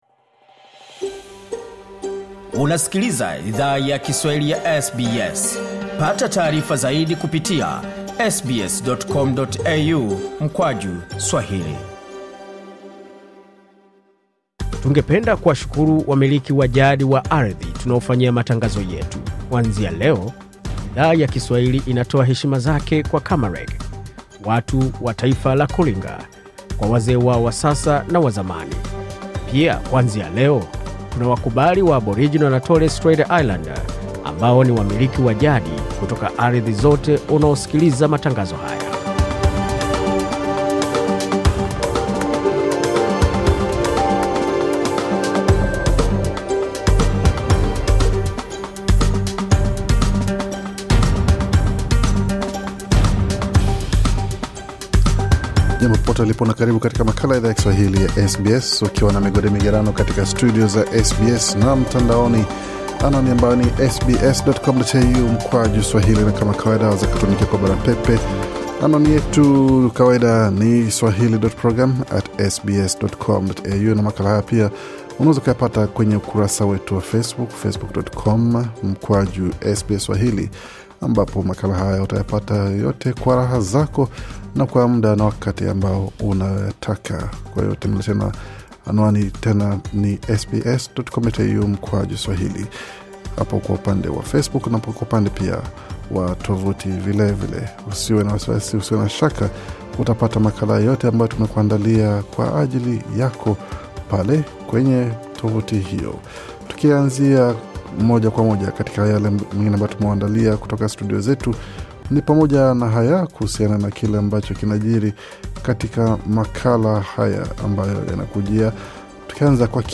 Taarifa ya Habari 24 Novemba 2023